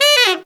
Index of /90_sSampleCDs/Zero-G - Phantom Horns/TENOR FX 2